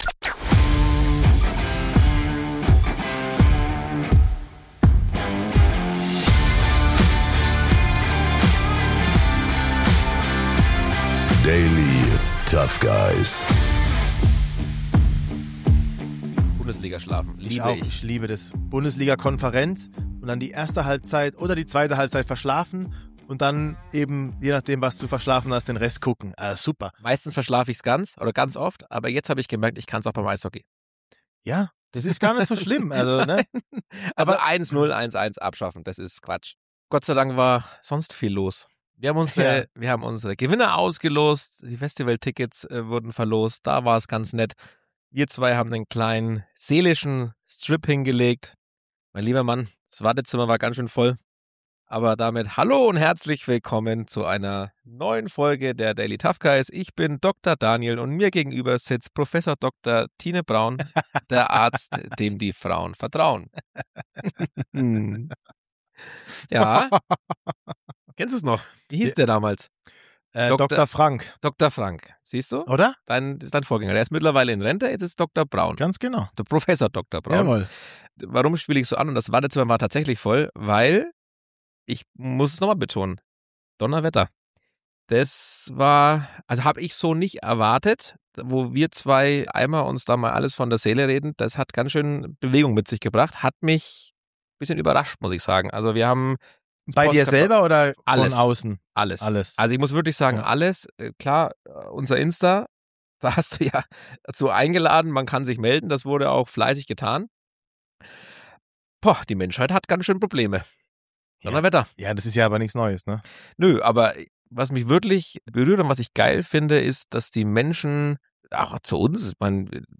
Beschreibung vor 2 Tagen Nach unserem letzten, etwas intensiveren Deep Talk melden wir uns zurück – diesmal wieder mit mehr Leichtigkeit, mehr Gelächter und dem ein oder anderen unnötigen, aber irgendwie doch wichtigen Gedankensprung :-D Wir sprechen über Mainstream: was wir heimlich feiern und was uns komplett überfordert. Dazu geht’s um Musik – die Konzerte, die wir gesehen haben oder gerne hätten und welche Titel uns für ein paar Minuten alles vergessen lassen.